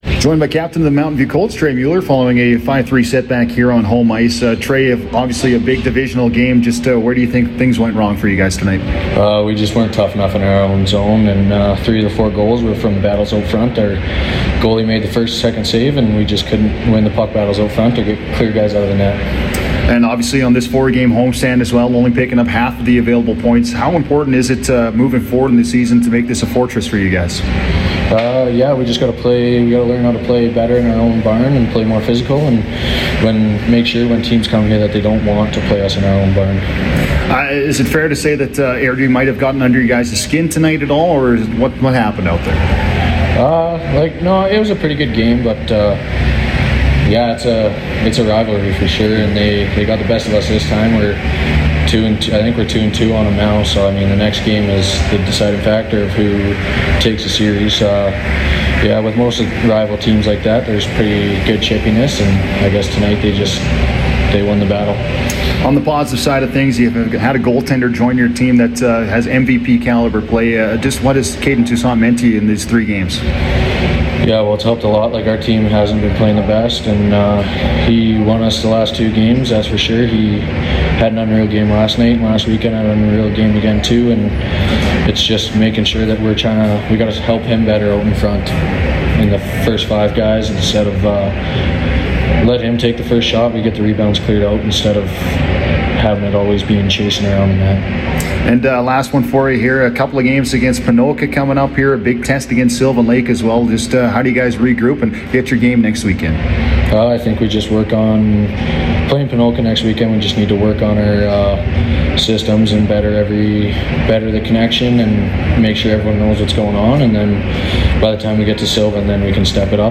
post-game conversation